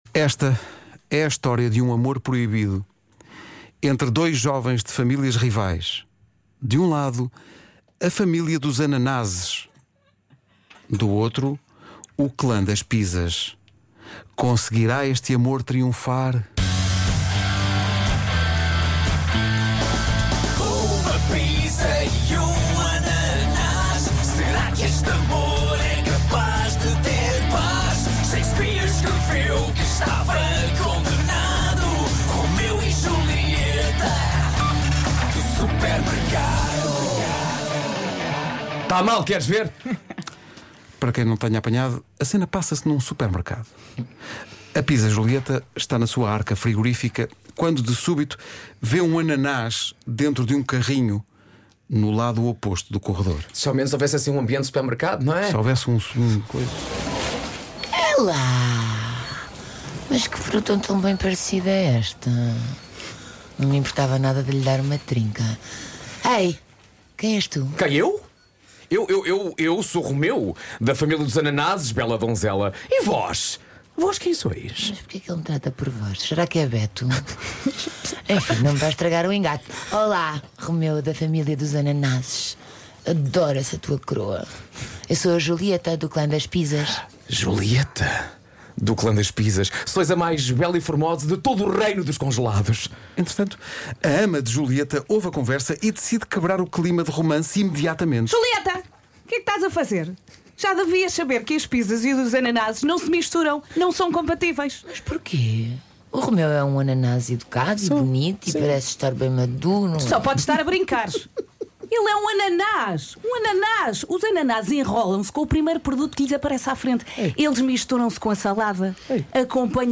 Radionovela das Manhãs da Comercial